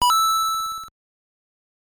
Здесь вы найдете как классические 8-битные эффекты из первых игр серии, так и более современные аудиофрагменты.
SFX5 звук подобранной монетки в Марио